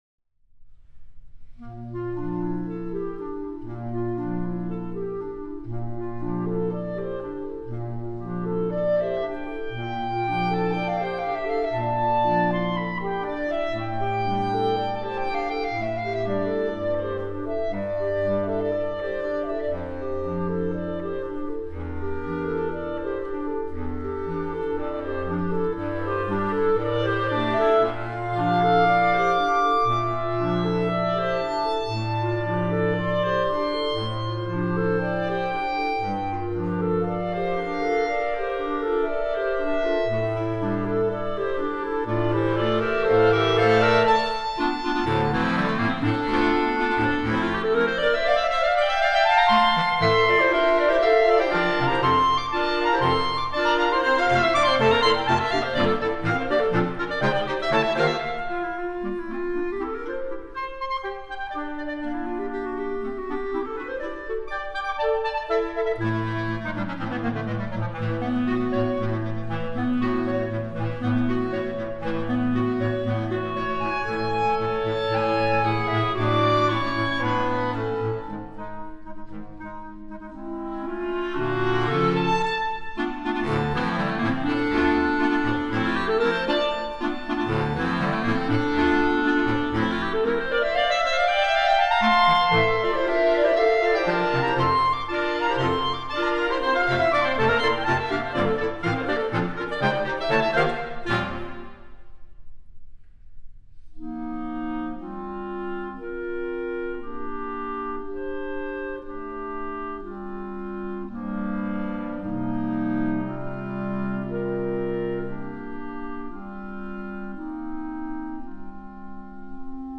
Noten für flexibles Ensemble.